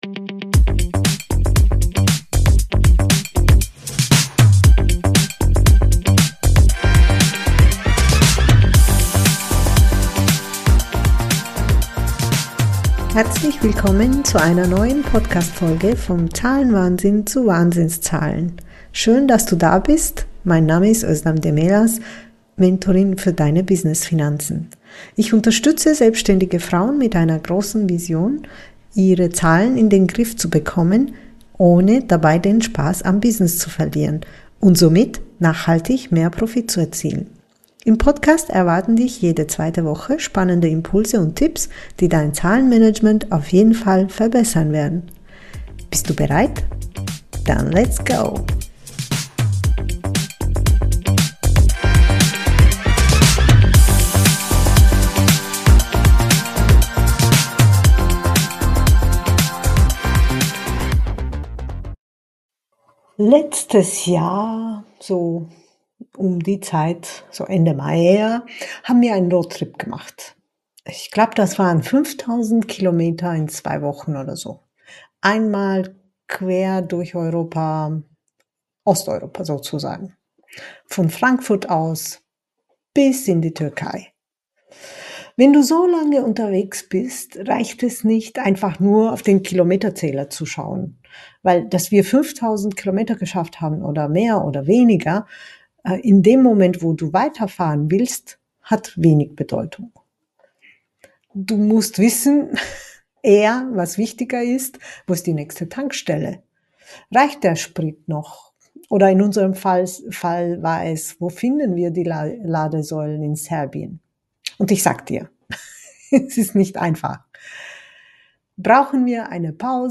Einfach Buchhaltung - Interview